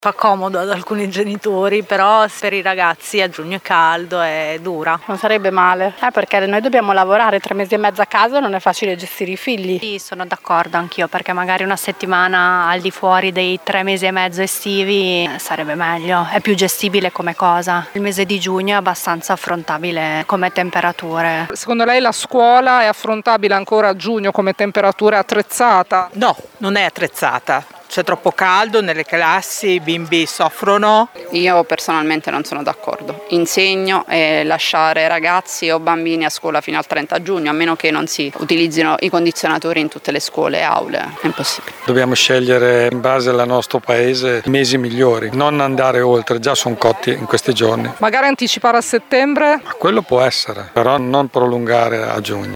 Sentite le interviste realizzate a mamme e papà di una scuola elementare di Modena: